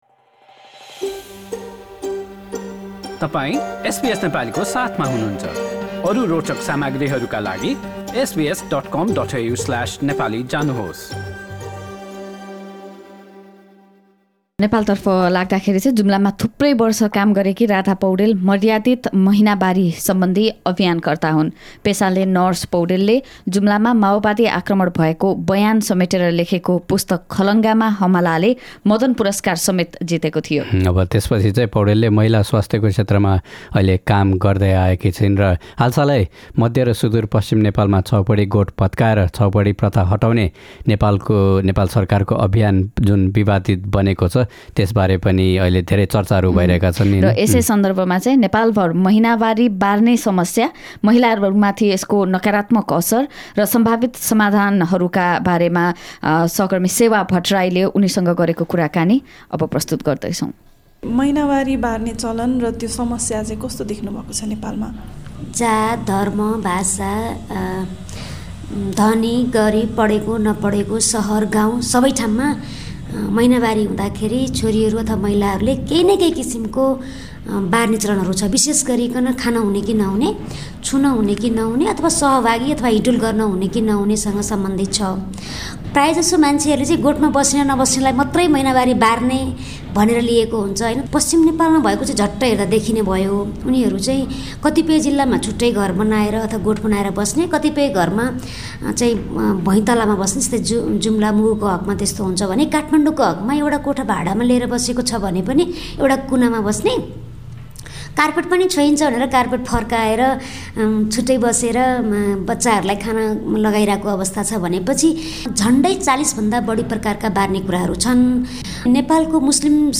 Dignified Menstruation campaigner
एसबीएस नेपाली पोडकास्ट